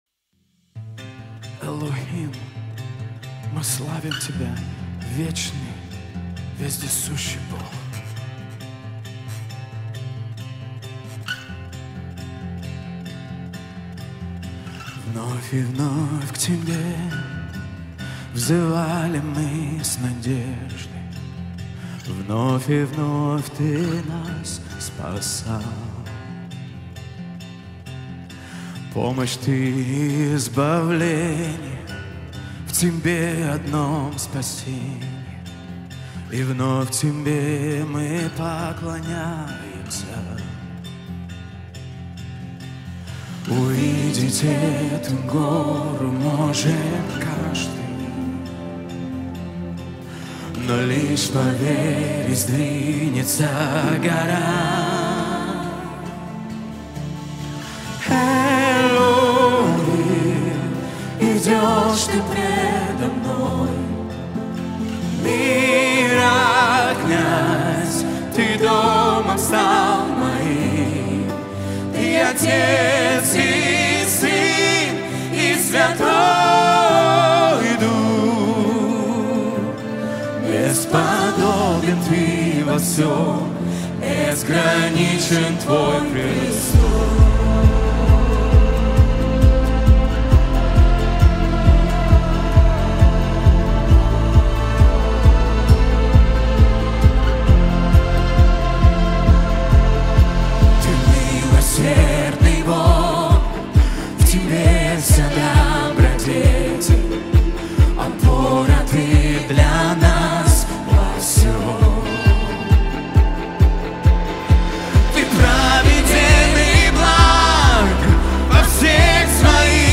173 просмотра 377 прослушиваний 7 скачиваний BPM: 67